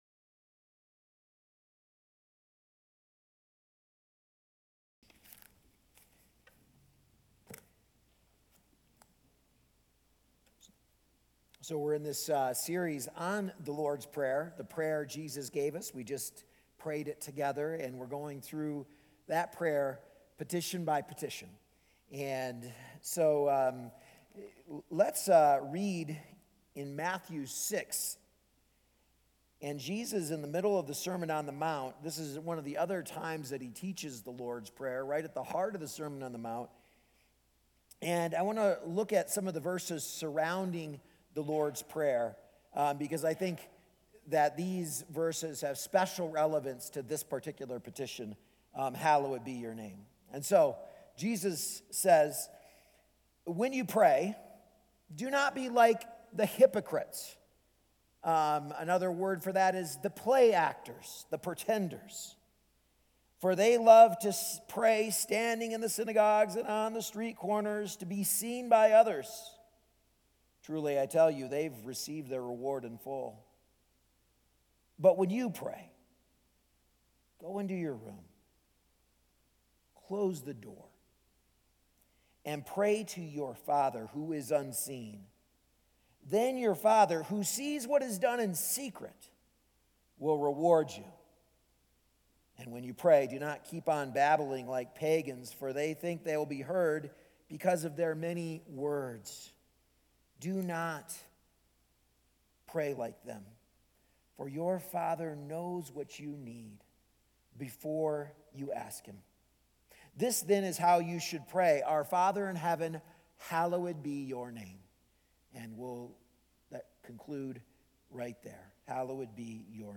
A message from the series "The Lord's Prayer."